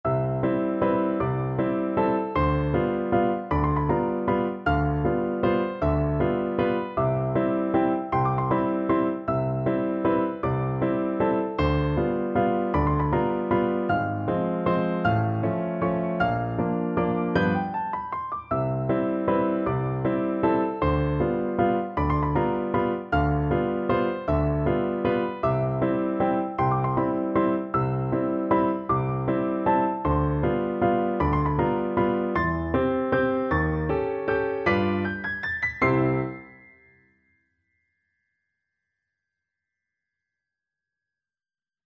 Free Sheet music for Piano Four Hands (Piano Duet)
3/4 (View more 3/4 Music)
Allegro grazioso . =c.52 (View more music marked Allegro)
Piano Duet  (View more Easy Piano Duet Music)
Classical (View more Classical Piano Duet Music)